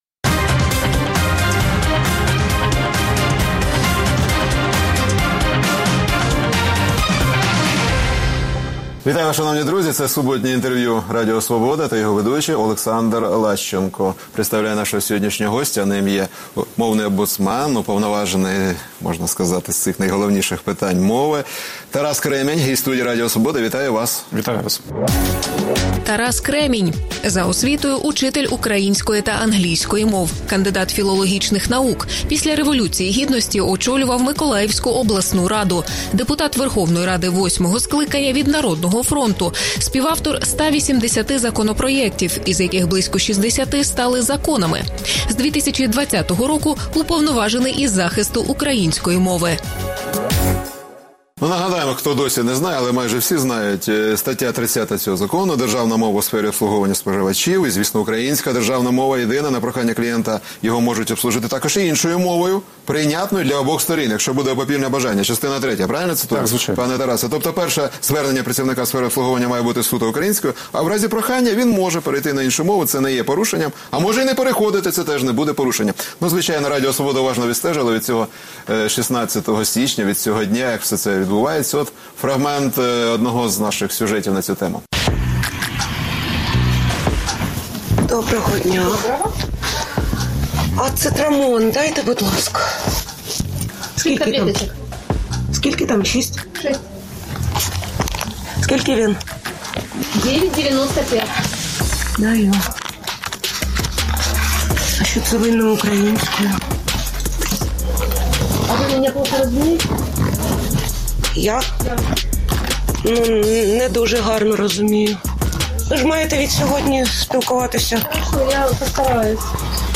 Суботнє інтерв’ю | Тарас Кремінь, мовний омбудсмен